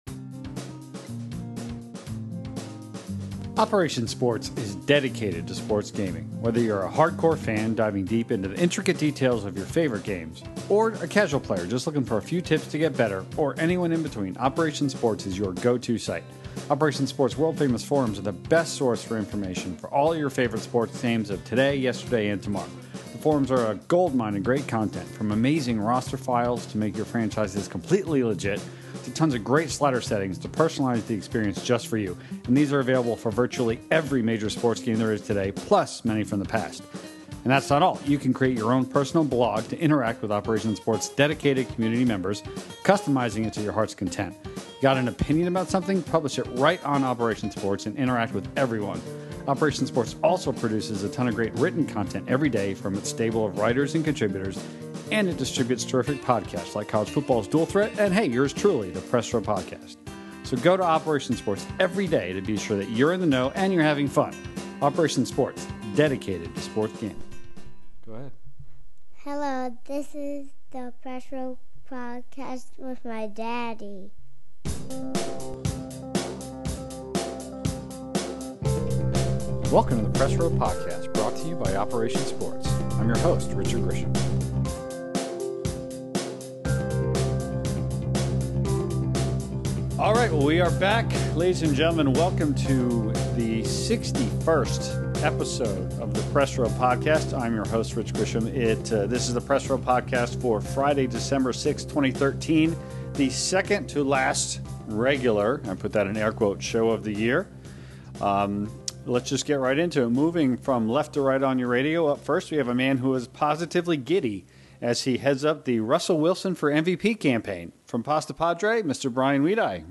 The 61st episode of the Press Row Podcast features a discussion about the top sports game stories of the year – and what a year it’s been. From the launching of new consoles to the cancellations and suspensions of multiple popular titles, there’s no lack of subjects from which to choose.